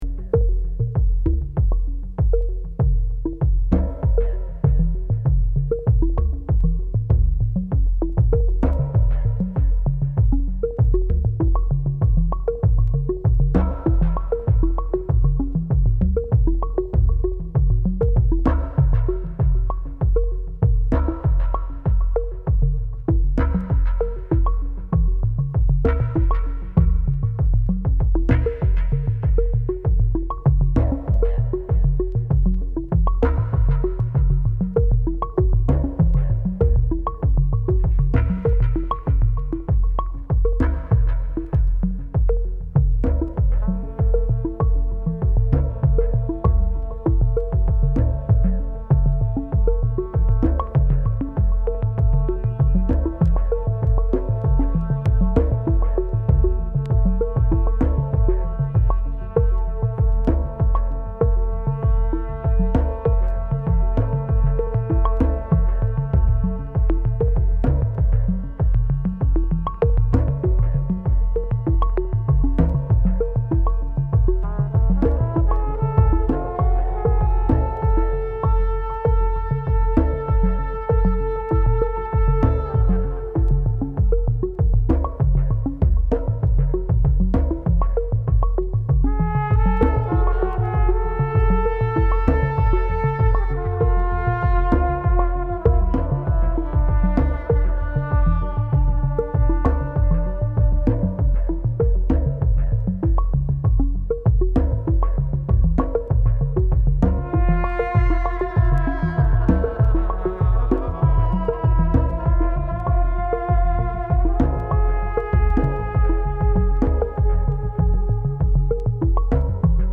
アップデートされたトラディショナル・ダンスミュージックを収録。